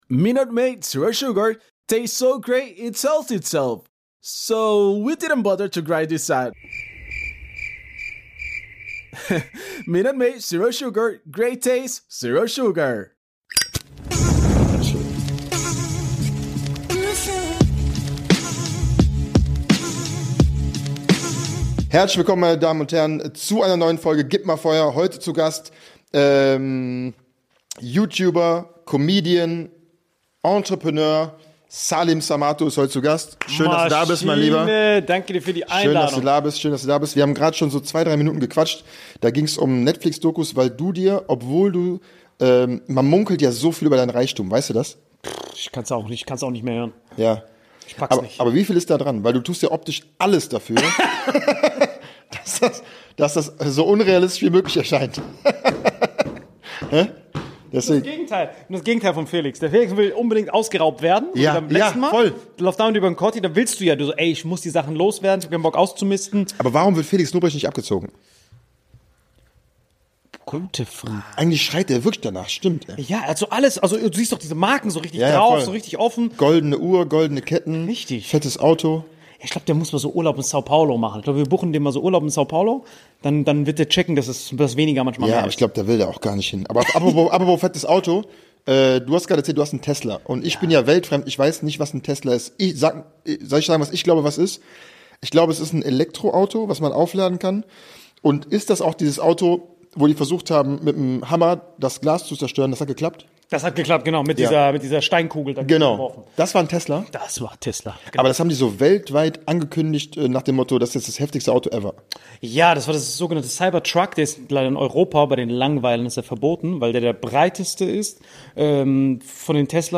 Es wird gelacht, geschwurbelt und noch mehr gelacht!